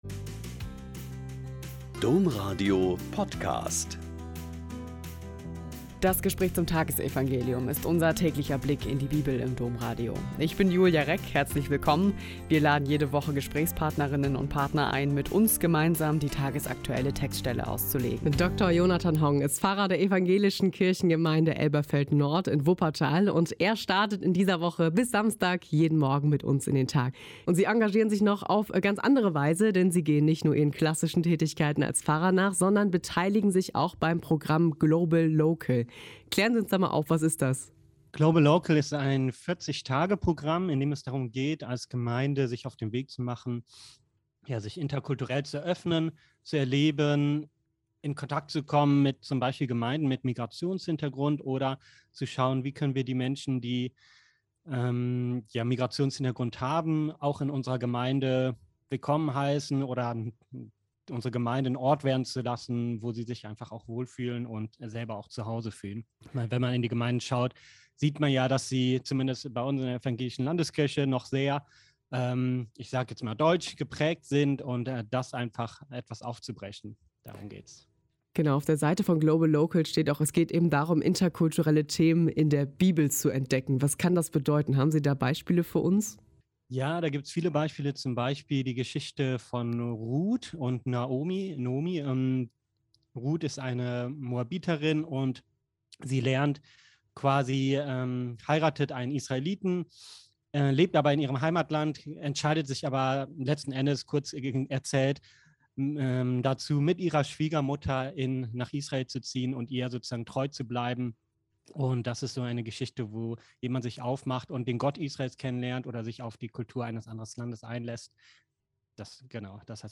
Mk 8,14-21 - Gespräch